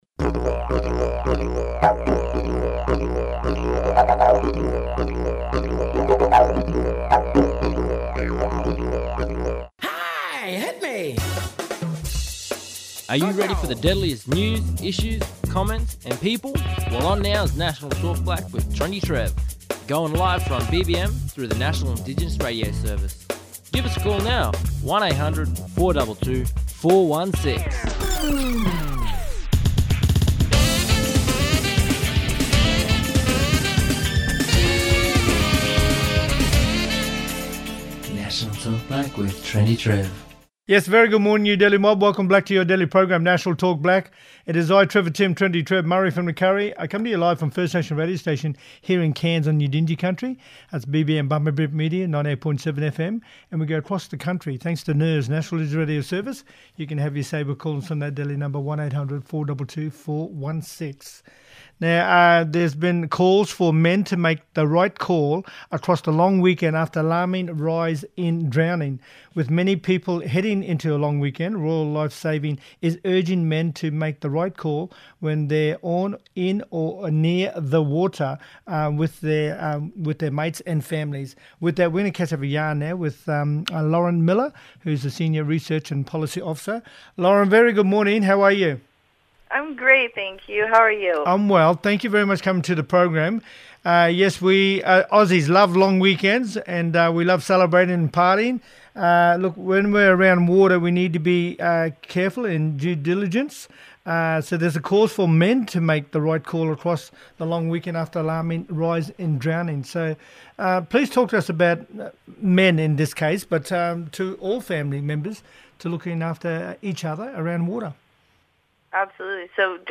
Gavin Wanganeen, speaking on the exhibition “The Lume”.